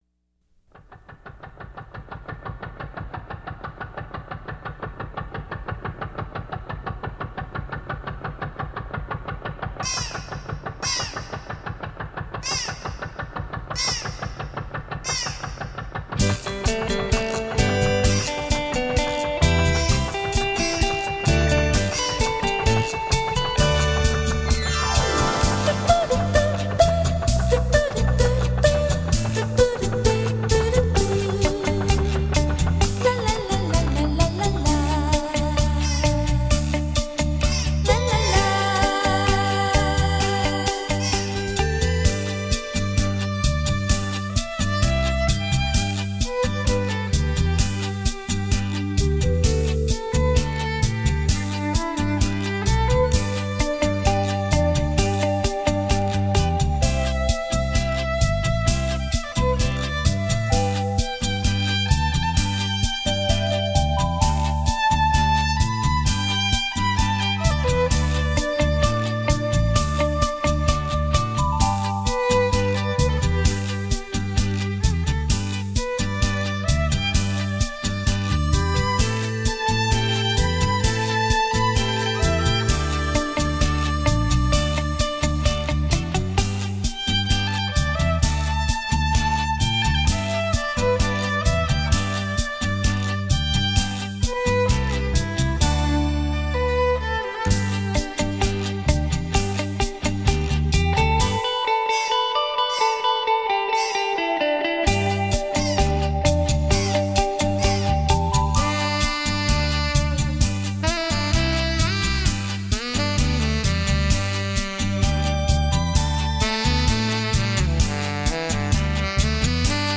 鱼船入港海鸟效果测试